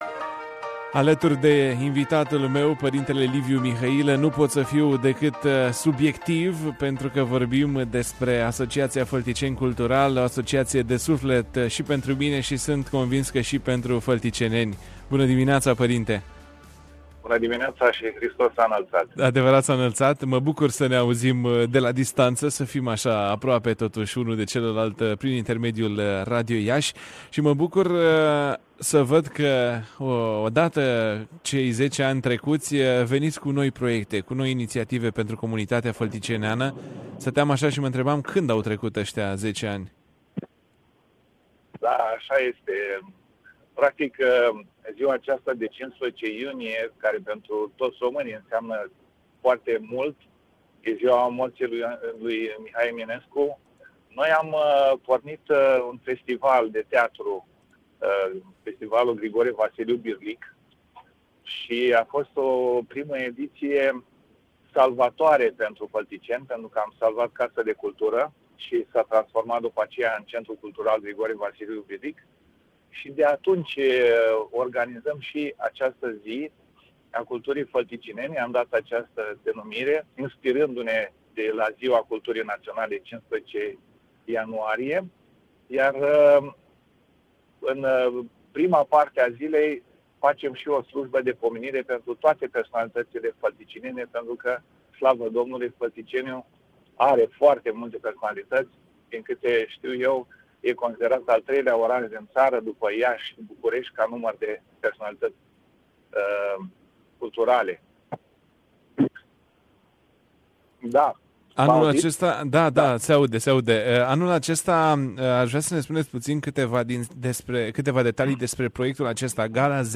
în direct în matinalul de la Radio România Iaşi